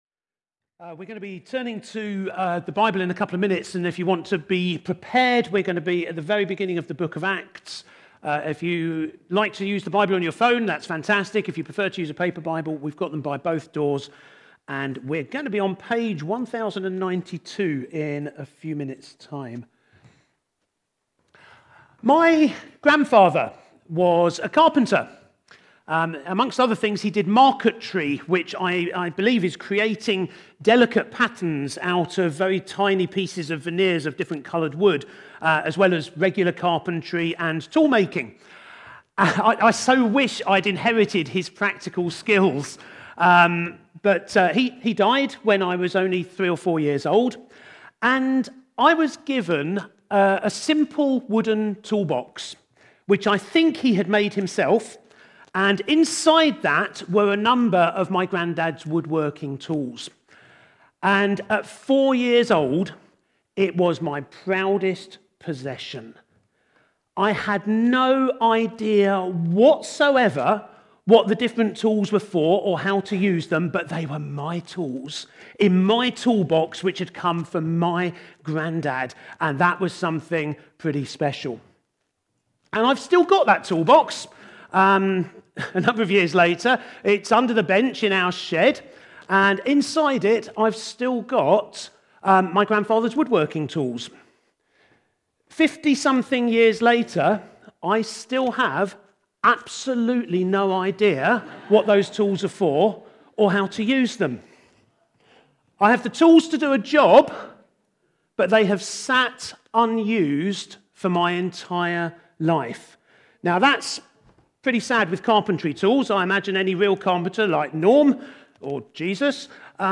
2nd-November-2025-Sermon.mp3